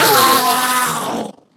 Minecraft Version Minecraft Version latest Latest Release | Latest Snapshot latest / assets / minecraft / sounds / mob / endermen / death.ogg Compare With Compare With Latest Release | Latest Snapshot
death.ogg